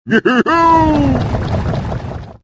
Clash Royale Bowler Laugh Sound Button - Free Download & Play